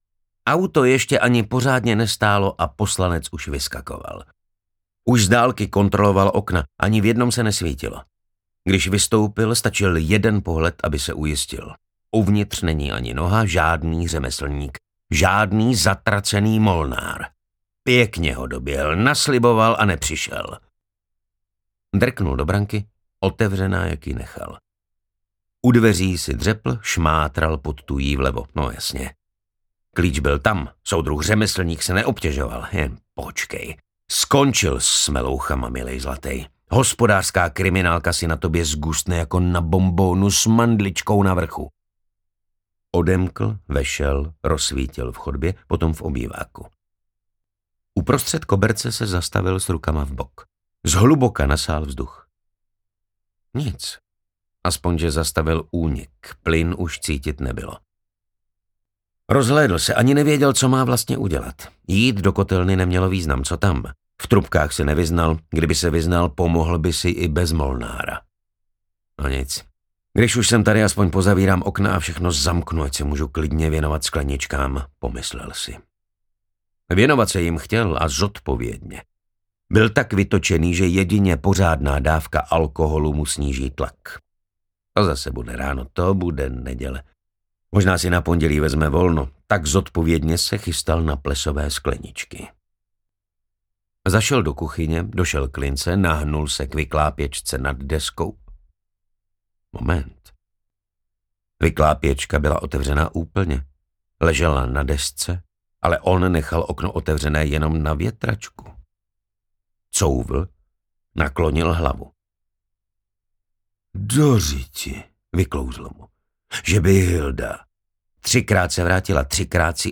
Dopis ze záhrobí audiokniha
Ukázka z knihy